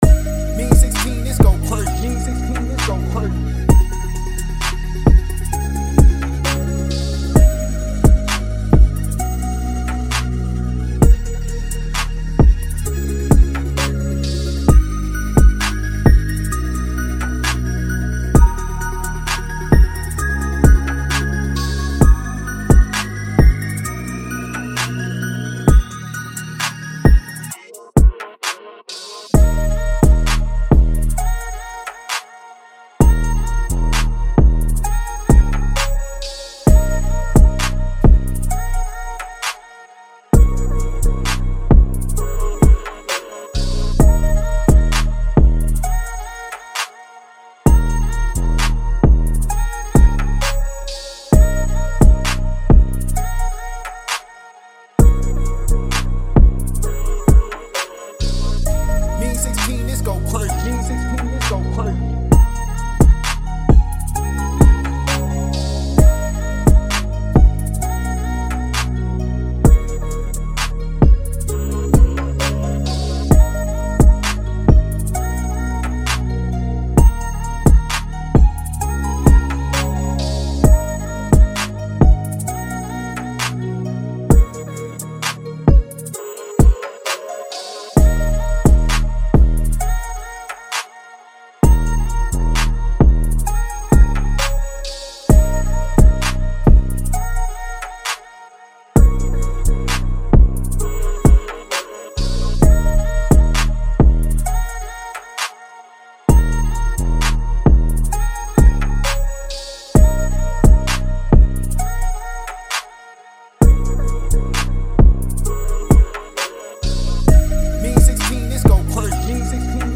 D#-Min 131-BPM